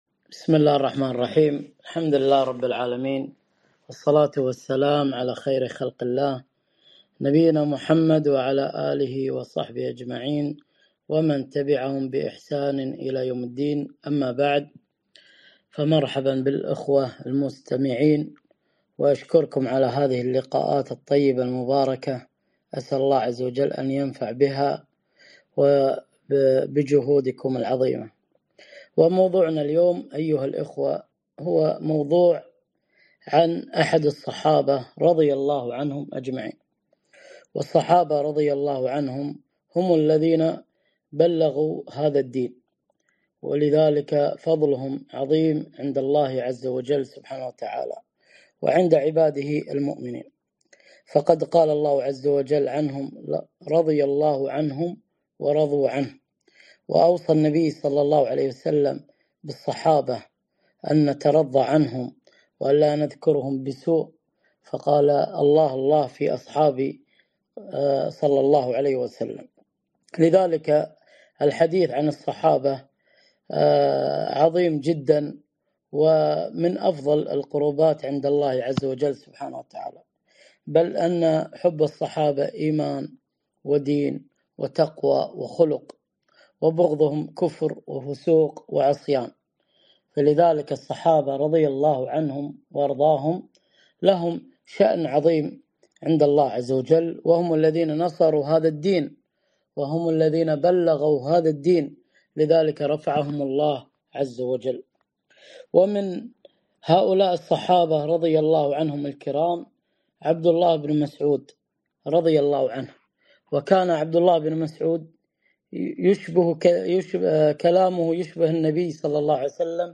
محاضرة - وصايا عبدالله بن مسعود رضي الله عنه